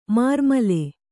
♪ mārmale